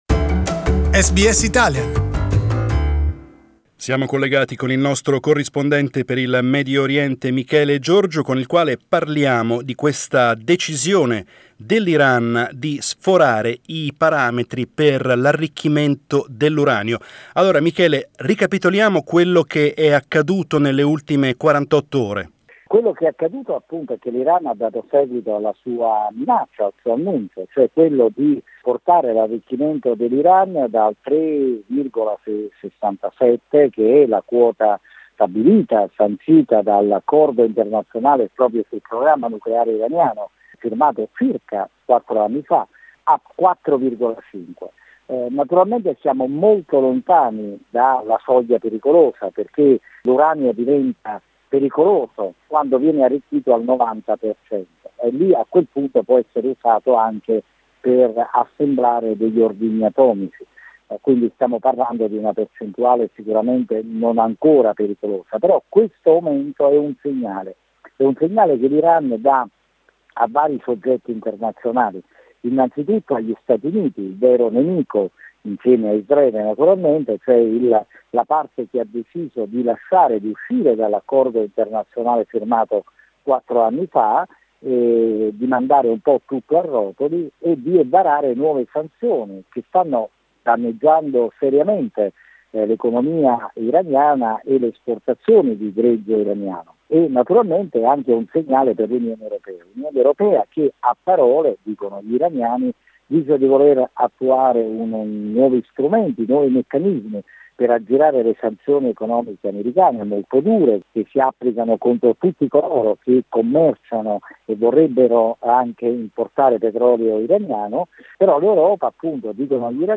Middle East correspondent